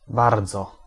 b b
bardzo bike